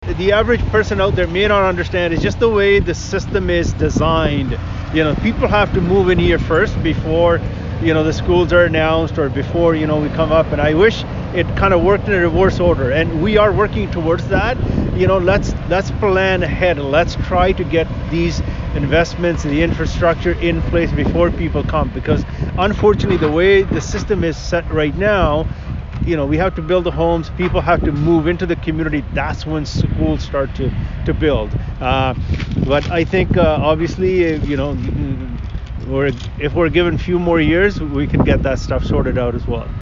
Milton MPP Parm Gill held a press conference at the building site along James Snow Parkway, just south of Louis St. Laurent Avenue on Monday morning.